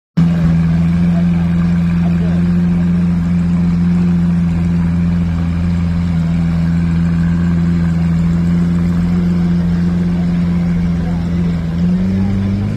Typical Yorkville Traffic.